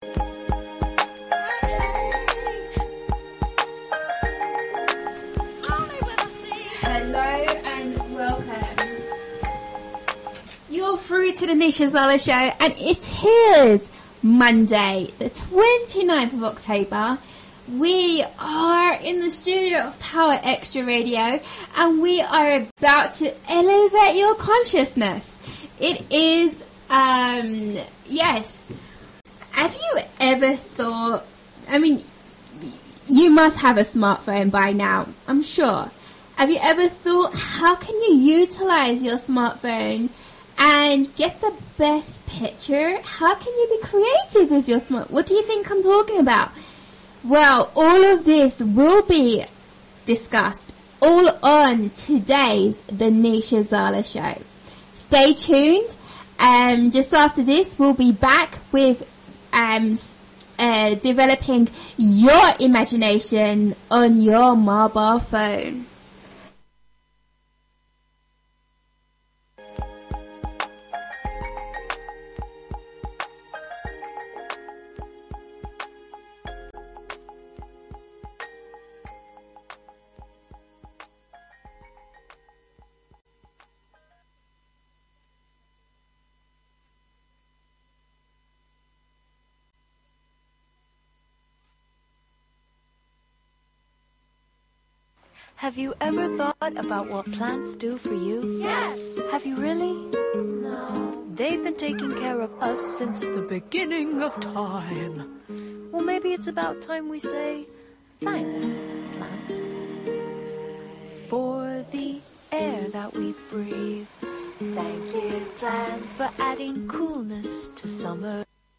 AUDIO OF RADIO INTERVIEW